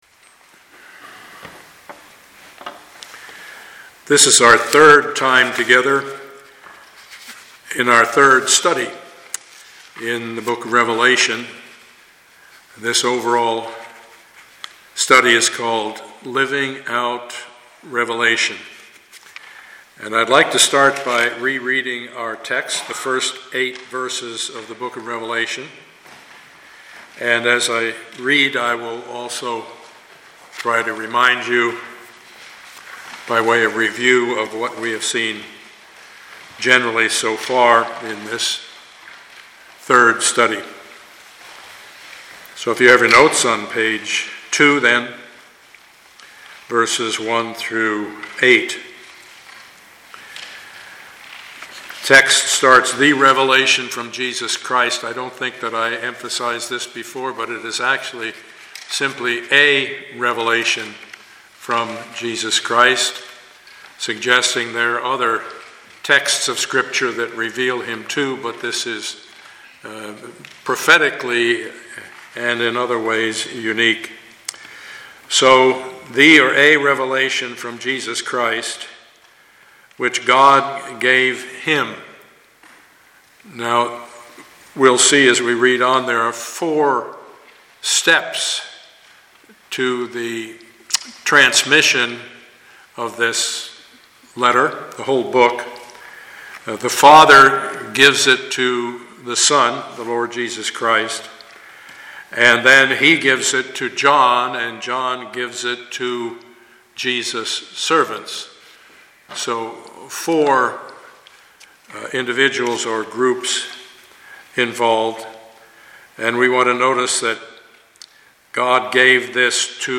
Passage: Revelation 1:1-8 Service Type: Sunday morning